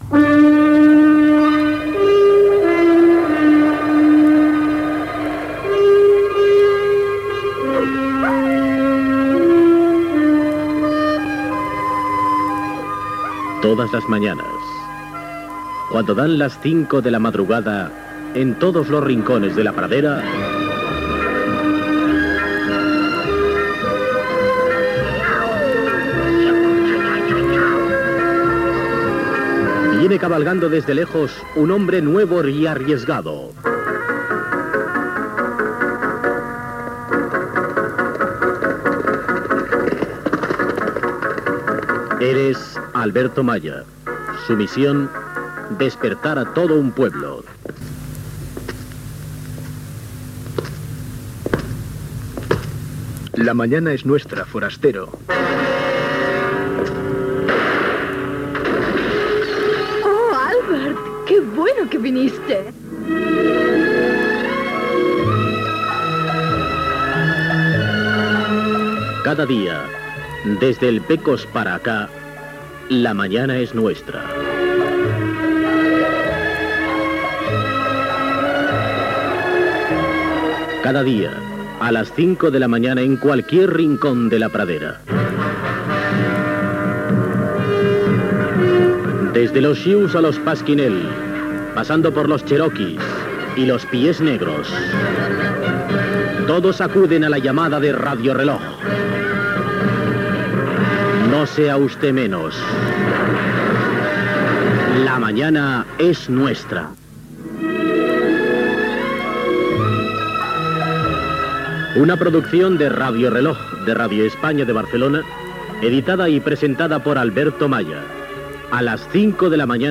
Promoció del programa.
Entreteniment